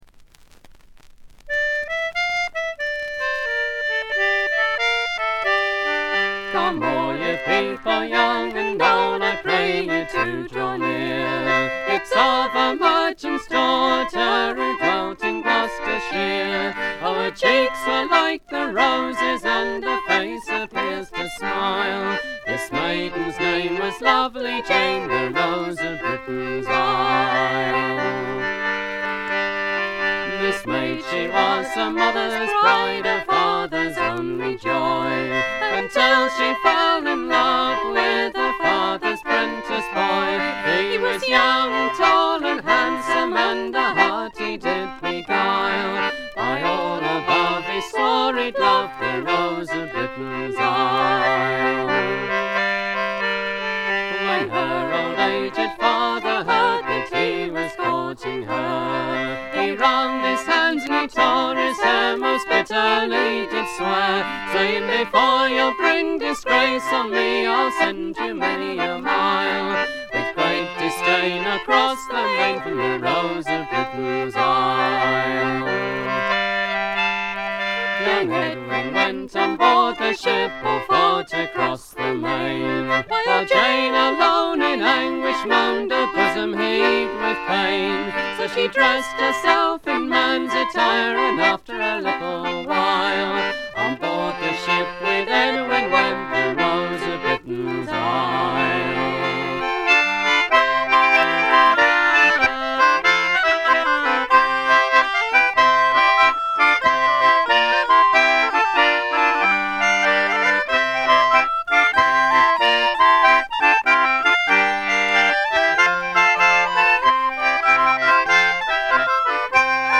静音部での軽微なチリプチ程度。
試聴曲は現品からの取り込み音源です。
button accordion, Anglo concertina, vocals
hammered dulcimer, oboe, vocals
Recorded at Livingston Studios, New Barnet, Herts.;